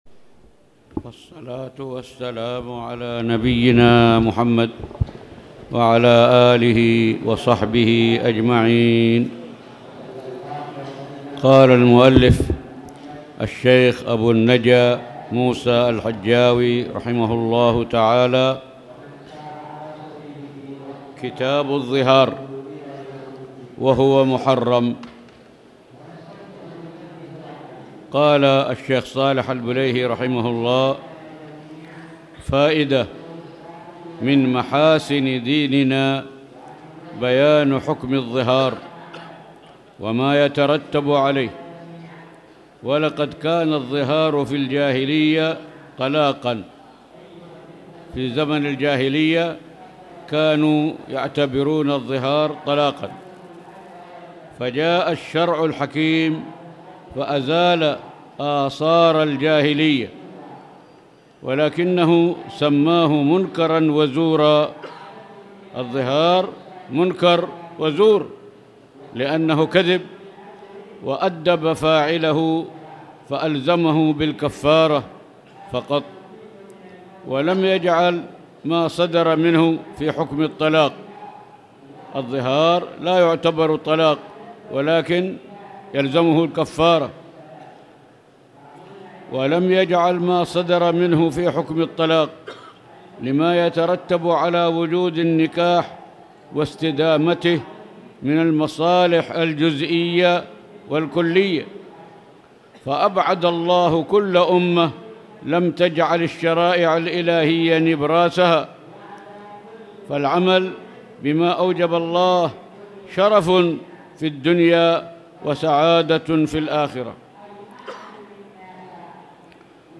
تاريخ النشر ١٨ ذو الحجة ١٤٣٧ هـ المكان: المسجد الحرام الشيخ